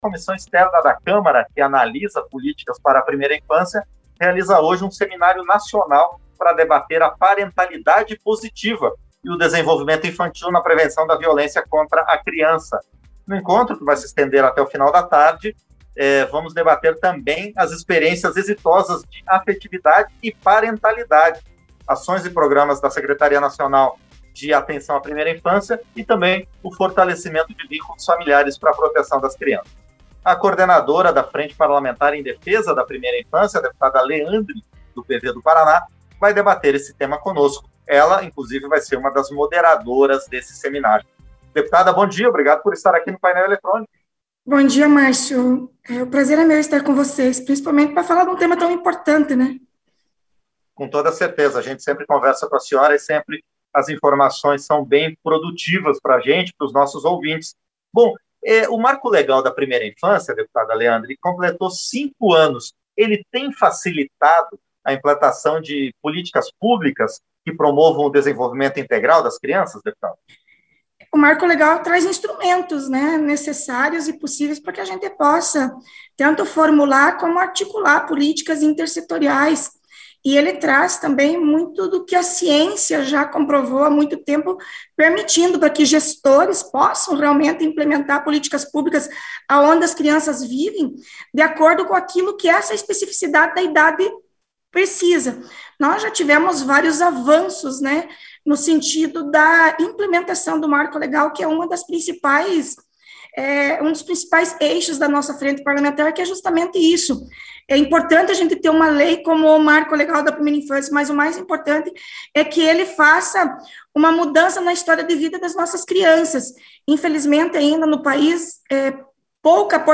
Entrevista - Dep. Leandre (PV-PR)